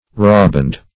Rawboned \Raw"boned`\ (-b[=o]nd`), a.